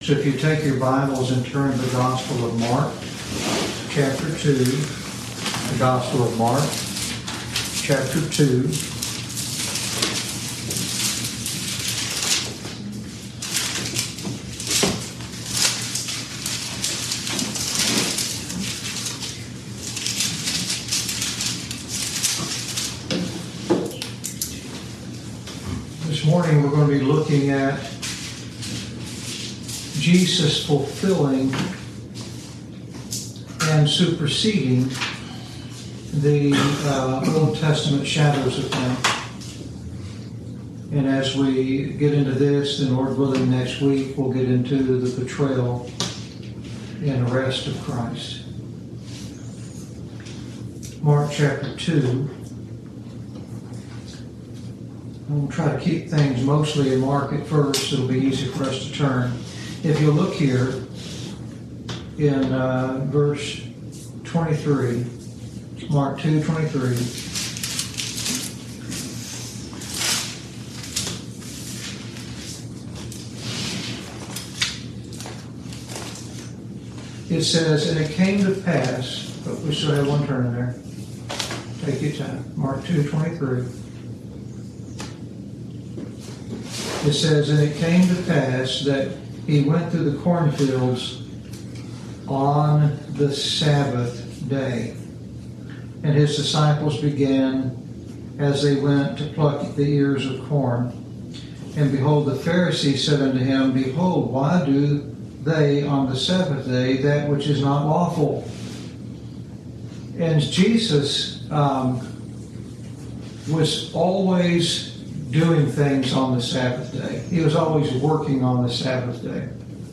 Lesson 37